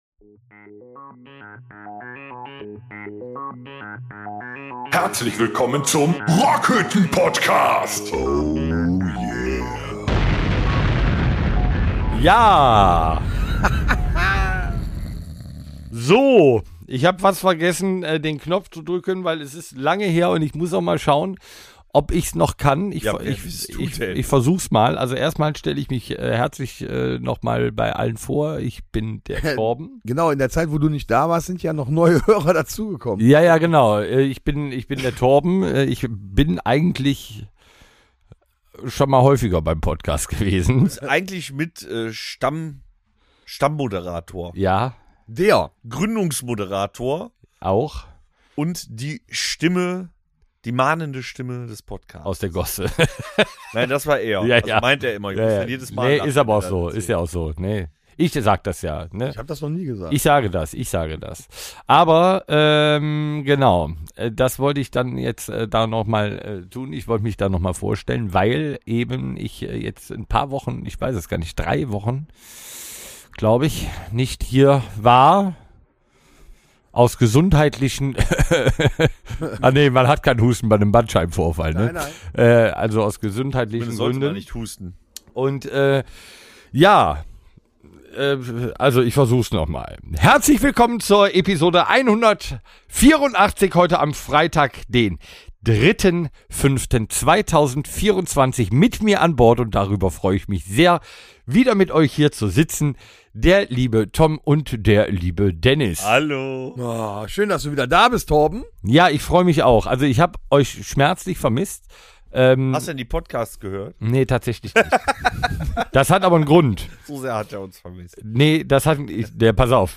Beschreibung vor 1 Jahr Wir gehen noch ein wenig auf die Streitfrage "Kiffen in Menschenmengen" ein und erinnern uns an den Tag der auf dem Papier unser Leben für immer veränderte: Den 18ten Geburtstag Werdet live Zeuge eines Unwetters von biblischem Ausmaß, bei dem beinahe die Rockhütte weggesprengt wird, spielt mit uns Promiraten, gönnt euch Musiktipps und setzt euch mal ne Runde damit auseinander, dass aus Asien definitv mehr kommt als nur Chinaschrott.